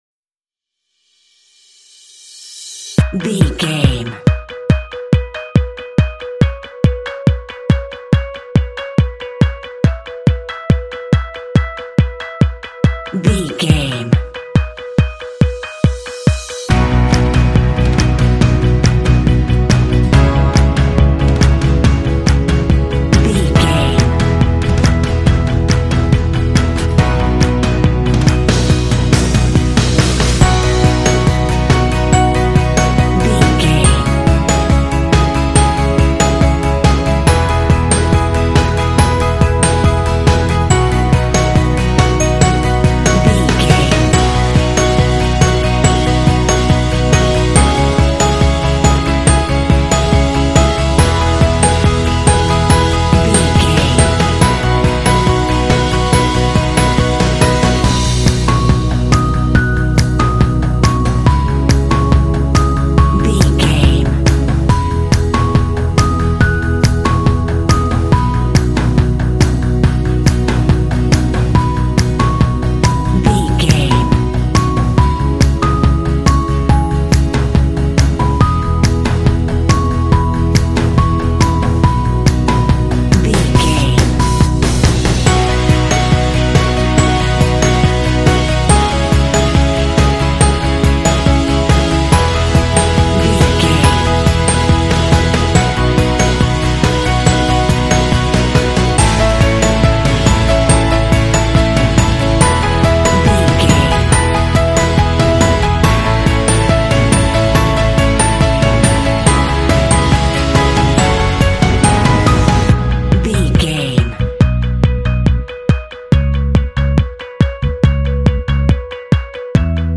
Uplifting
Ionian/Major
driving
joyful
electric guitar
bass guitar
drums
percussion
piano
acoustic guitar
rock
pop
alternative rock
indie